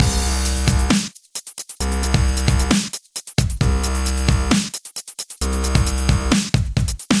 Step 2 – Adding an LFO
Here’s how the patch sounds so far, alongide a beat at 133 bpm (I’m just playing some 2 beat long notes at the beginning of each bar):